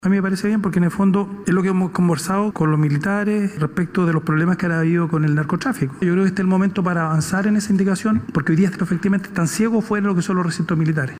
El diputado de Amarillos, Andrés Jouannet, valoró la propuesta del Ejecutivo, apuntando que es consistente con las preocupaciones que ha conocido del propio mundo militar.